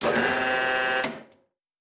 pgs/Assets/Audio/Sci-Fi Sounds/Mechanical/Servo Big 8_1.wav at master
Servo Big 8_1.wav